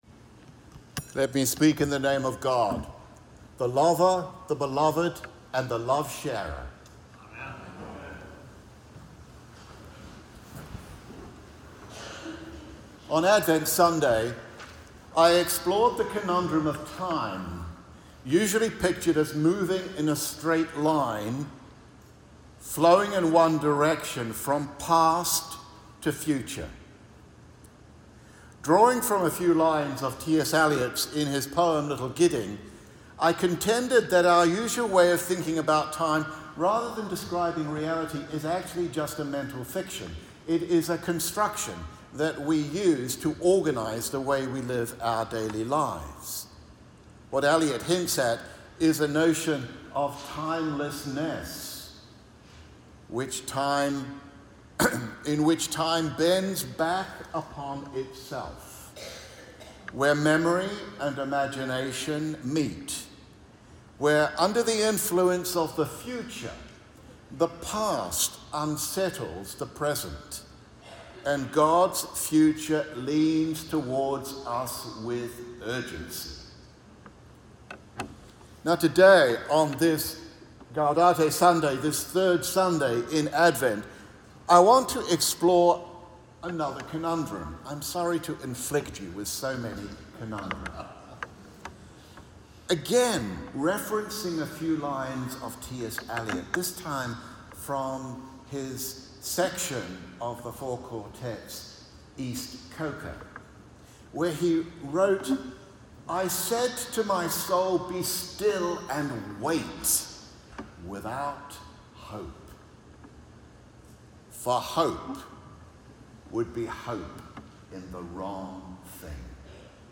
Audio: Note the recording is a version of the text below, streamlined for oral delivery